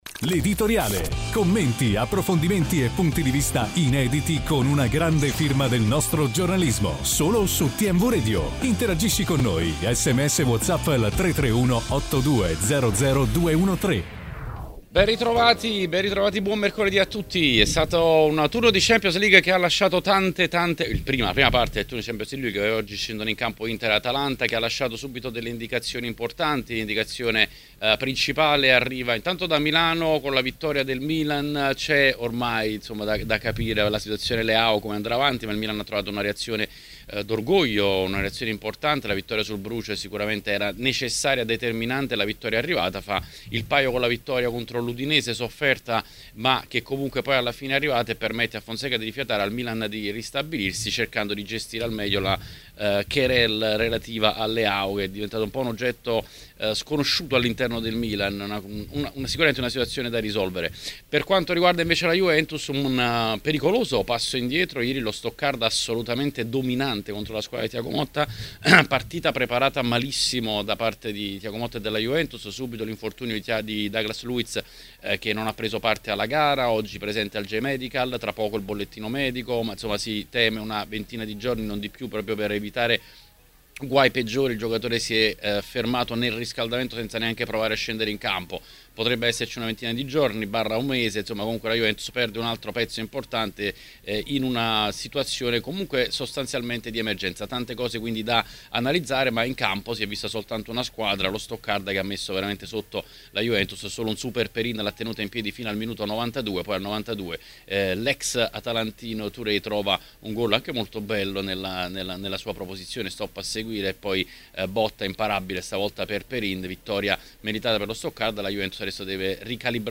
A Tmw Radio nel corso dell’Editoriale queste sono state le parole del direttore Xavier Jacobelli nel suo intervento odierno.